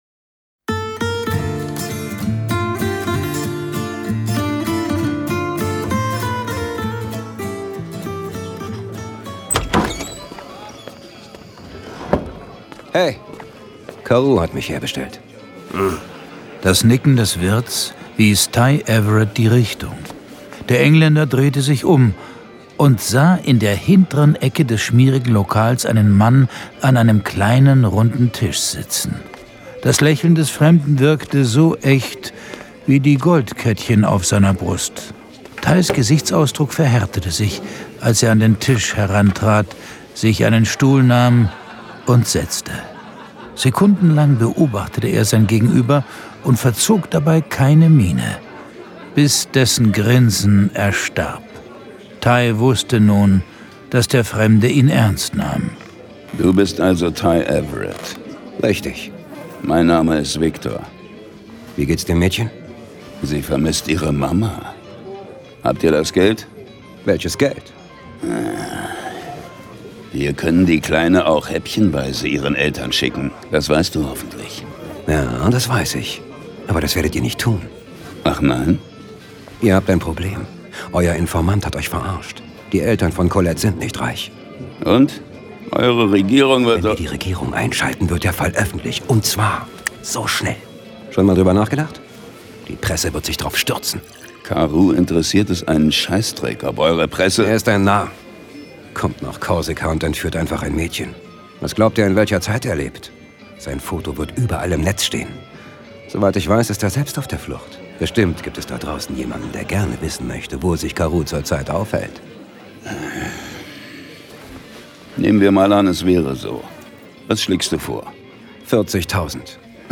John Sinclair - Folge 64 Um Mitternacht am Galgenberg. Hörspiel.